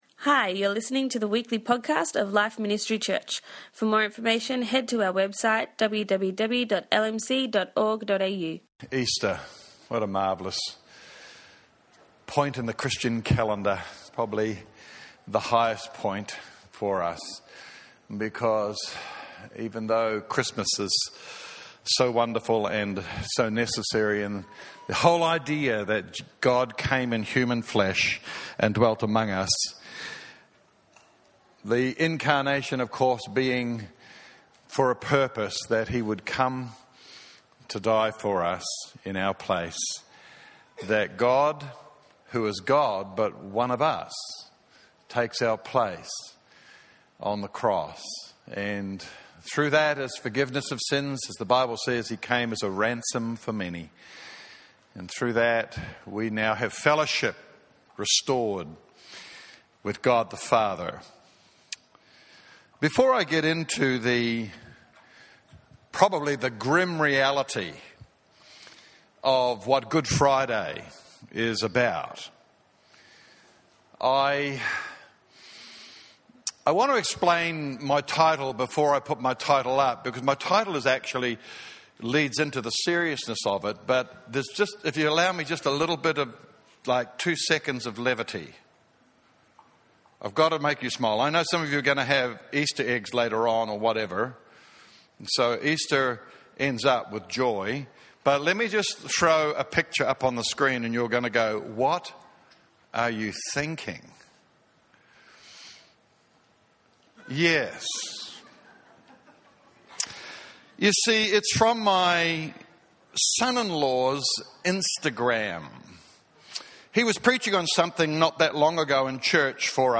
This message is from our Good Friday service.